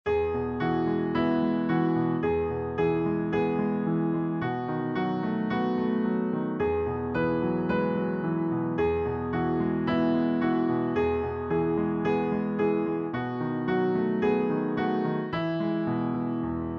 Children's Nursery Rhyme Lyrics and Sound Clip